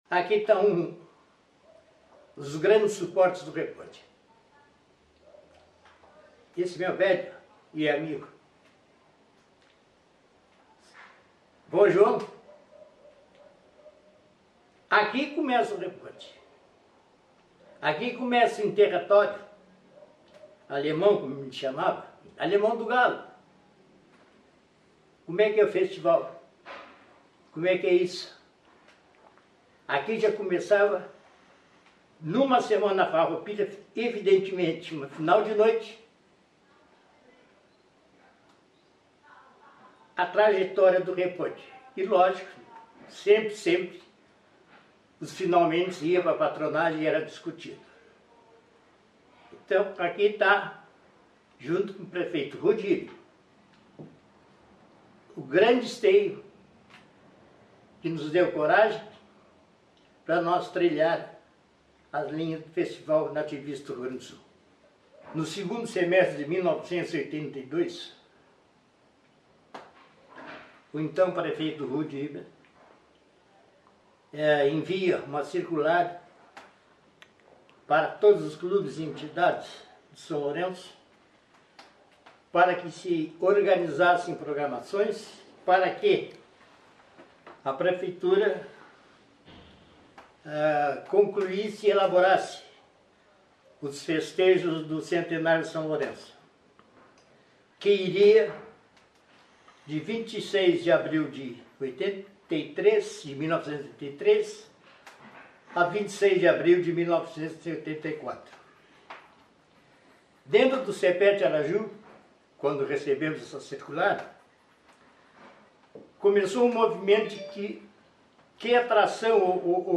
Em um dos últimos depoimentos gravados